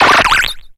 Cri de Cheniti dans Pokémon X et Y.